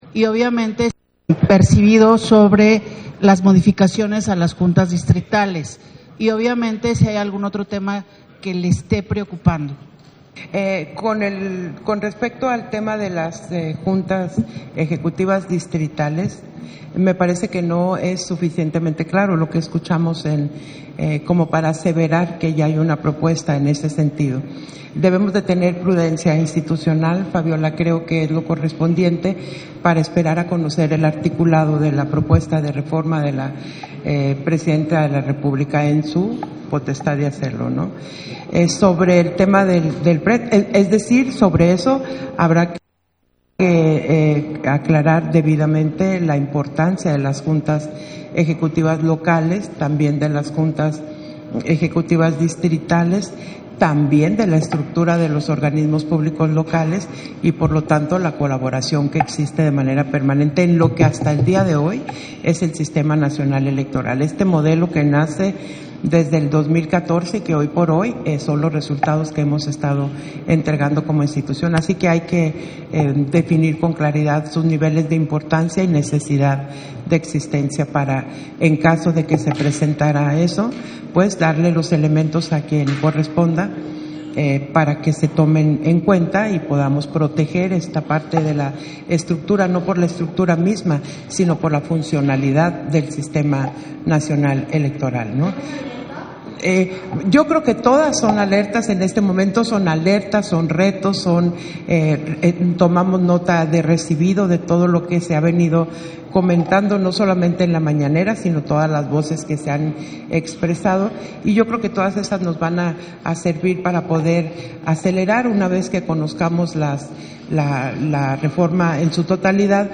Audio de la conferencia de prensa que ofreció la Consejera Presidenta del INE, Guadalupe Taddei, al termino de la sesión del Consejo General